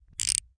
Part_Assembly_02.wav